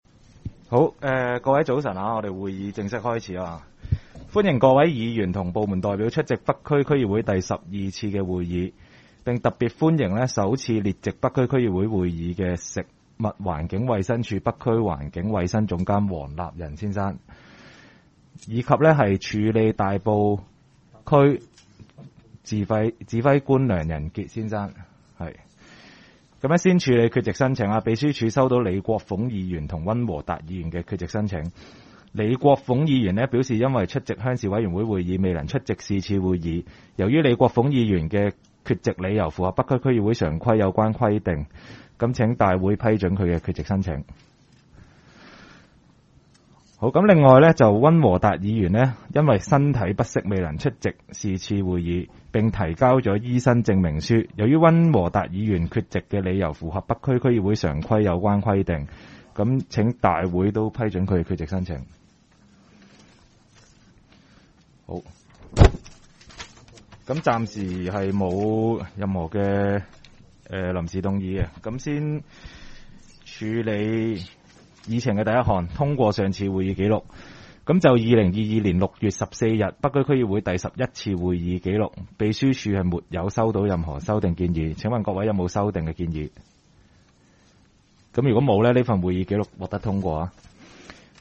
区议会大会的录音记录
北区区议会第十二次会议
北区民政事务处会议室